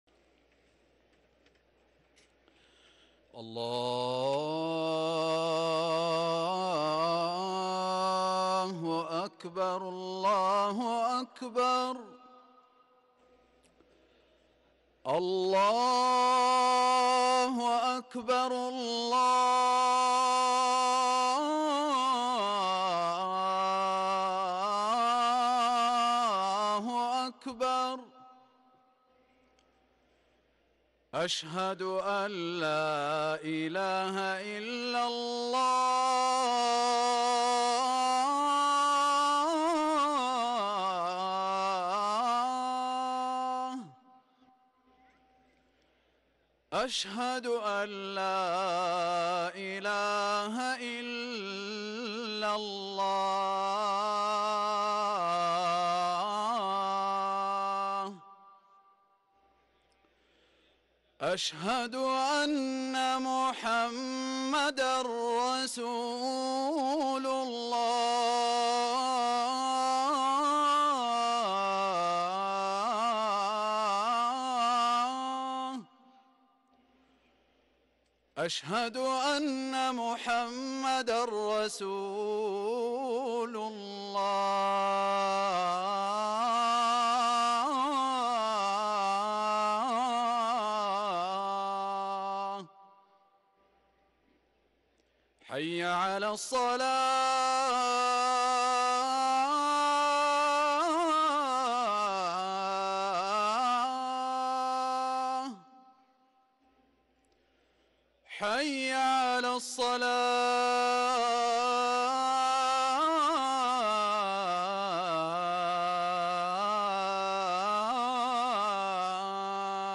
اذان العشاء